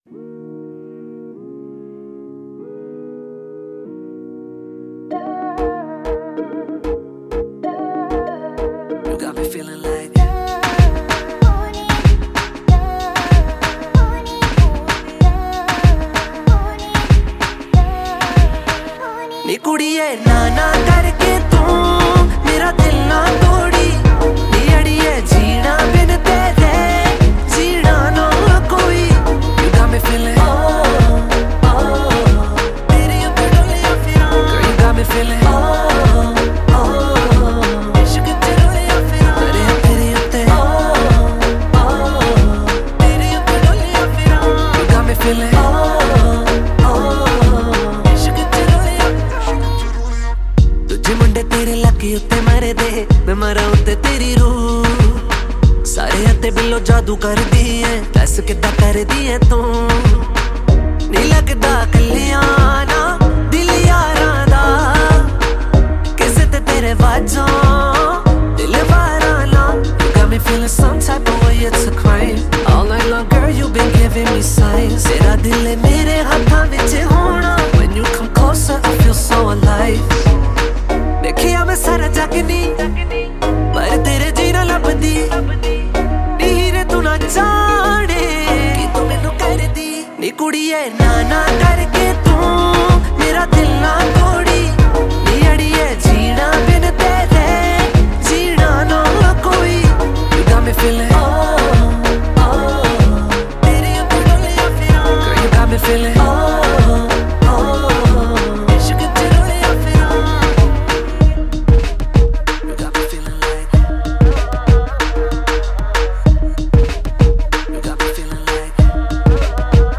Punjabi Songs